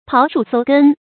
刨樹搜根 注音： ㄆㄠˊ ㄕㄨˋ ㄙㄡ ㄍㄣ 讀音讀法： 意思解釋： 喻追究底細。